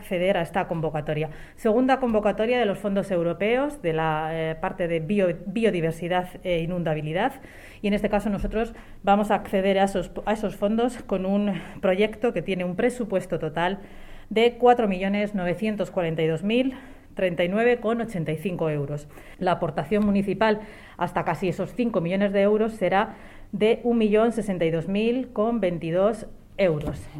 La portavoz del equipo de Gobierno, Noelia de la Cruz, ha informado en rueda de prensa de los proyectos y expedientes tratados en la Junta de Gobierno Local, entre los que ha destacado el proyecto que el Ayuntamiento de Toledo ha presentado a la segunda convocatoria de subvenciones para fomentar actuaciones dirigidas a la restauración de ecosistemas fluviales y a la reducción del riesgo de inundación de entornos urbanos.
AUDIOS. Noelia de la Cruz, portavoz del equipo de Gobierno